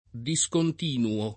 [ di S kont & nuo ]